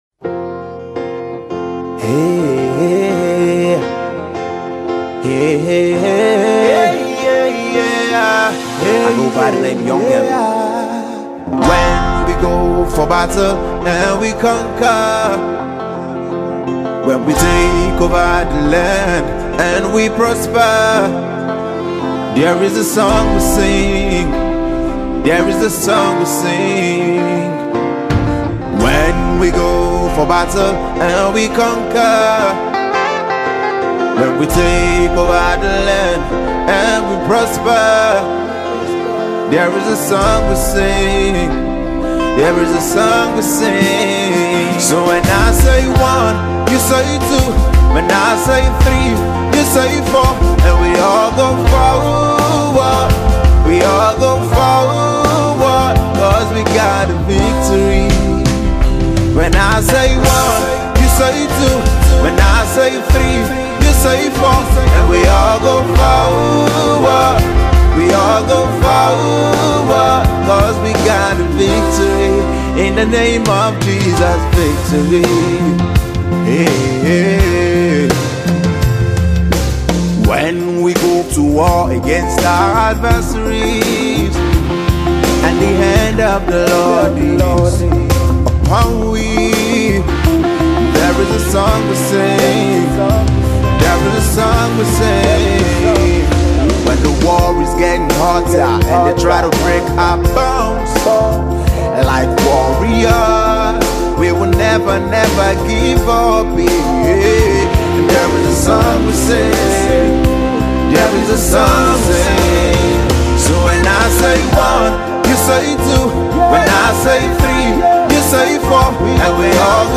Gospel/Inspirational music artist
mid tempo powerful Gospel tune